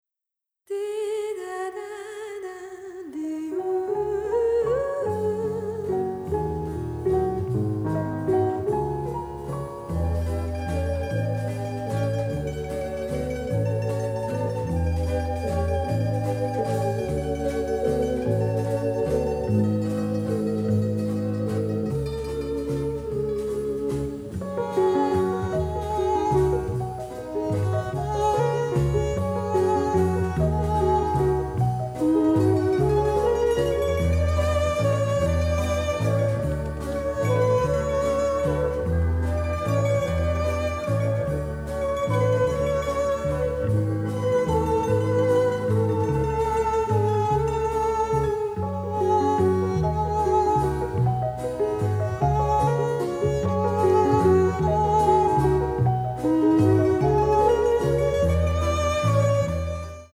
soundtrack album
builds upon classic Italian songs
original stereo session elements